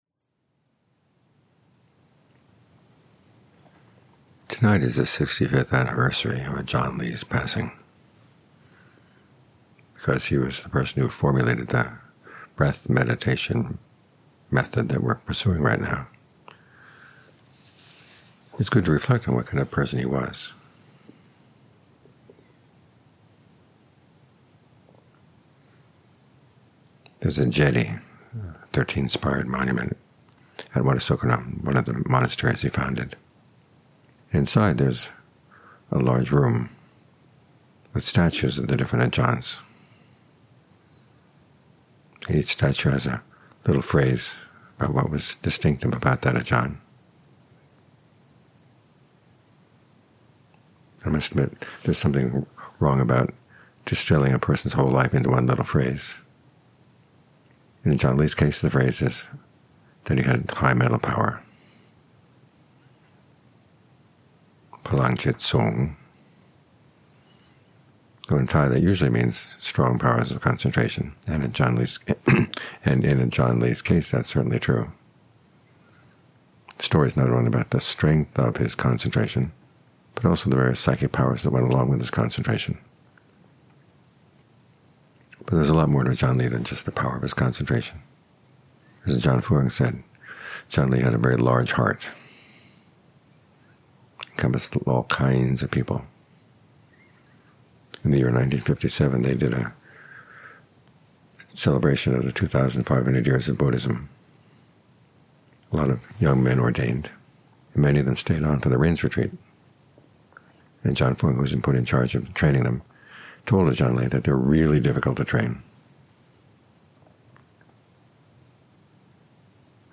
Evening Talks